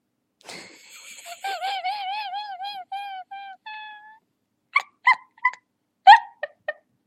Giggle
Category: Sound FX   Right: Personal